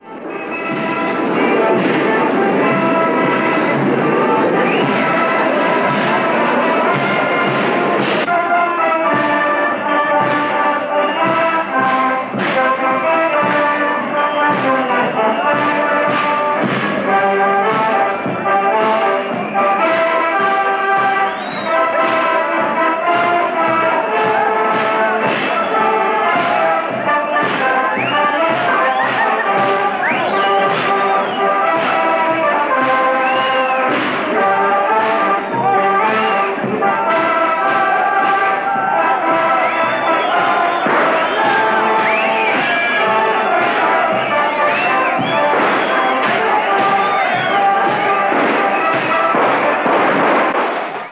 А теперь послушайте звуки совсем другого «парада», который за три четверти века до нашего могли видеть совсем другие зрители и совсем в другом месте. Качество записи очень неважное… но просто послушайте и оцените: